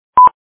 timeTick.mp3